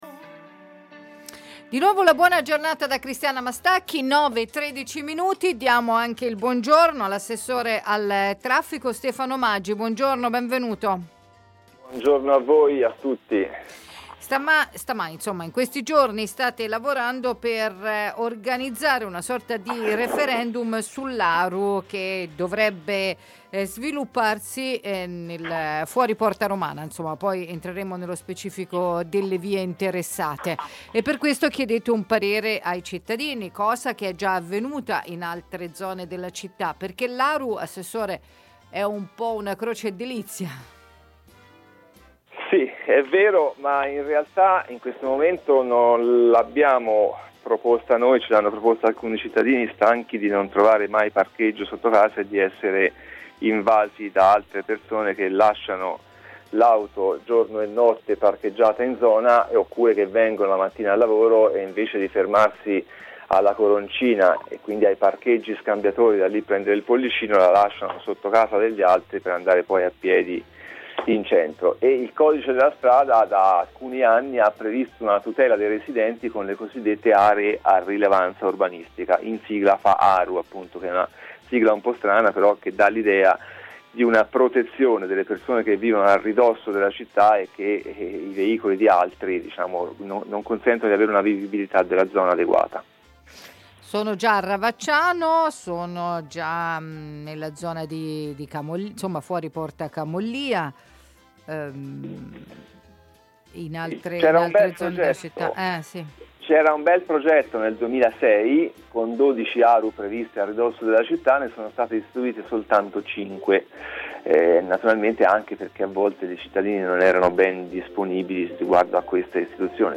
Referendum tra i cittadini per decidere della nuova Aru nella zona fuori porta Romana. Sono i residenti ad aver chiesto di destinare i parcheggi nelle strade adiacenti al centro a parcheggio riservato come ha detto l’assessore al traffico Stefano Maggi stamani ad Antenna Radio Esse.